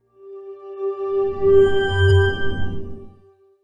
Windows X7 Startup.wav